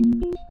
logout.ogg